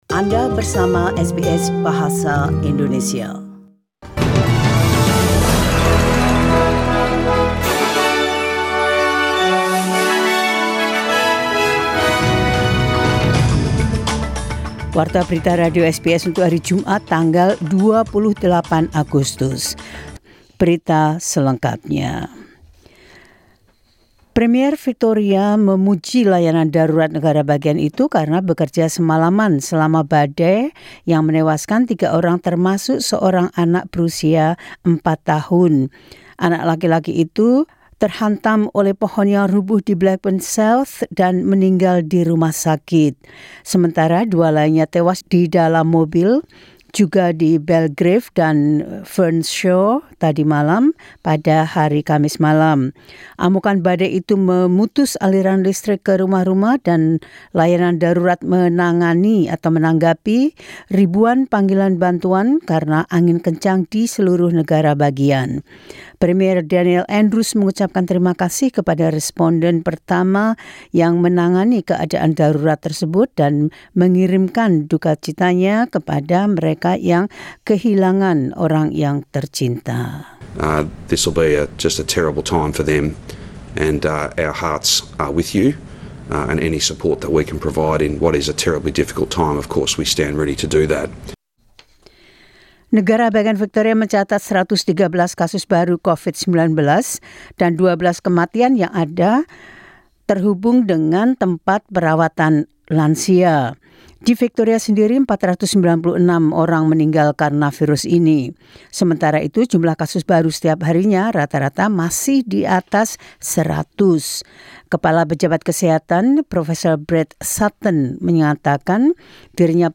SBS News Indonesian Program - 28 Aug 2020.